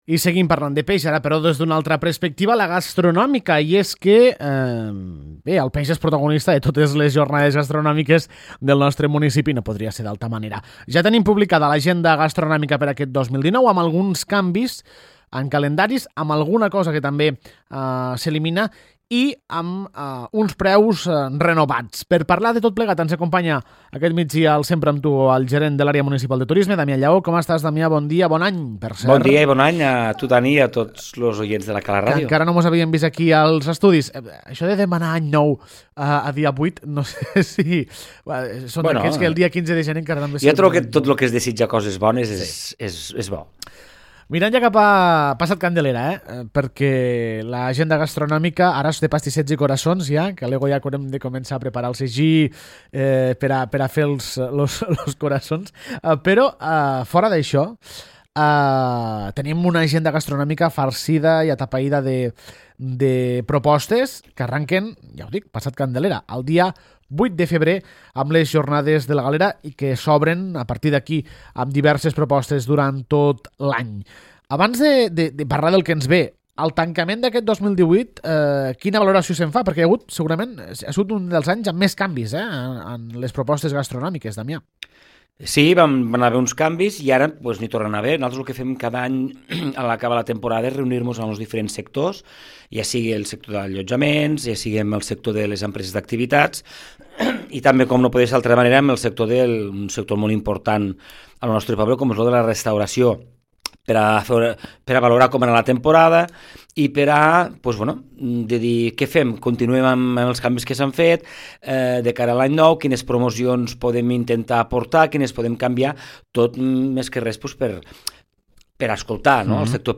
L’entrevista